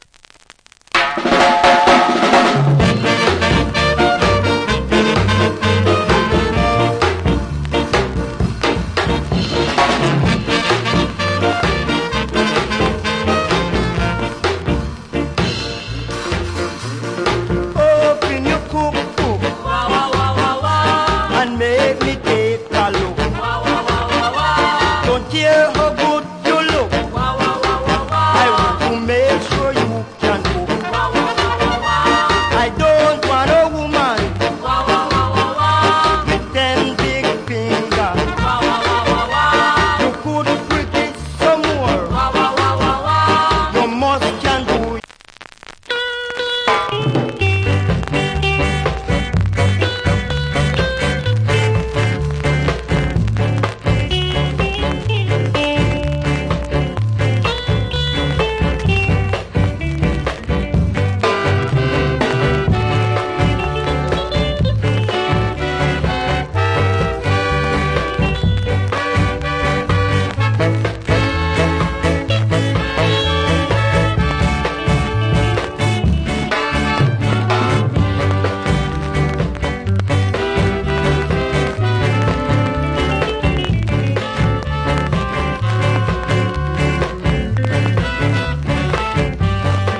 Great Ska Vocal.